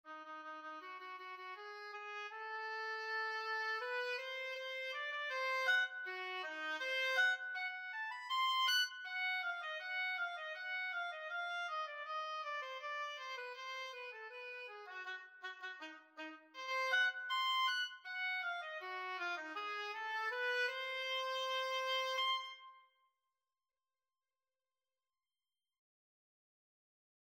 Middle Eastern scale a
oboe-sibelius-audio.mp3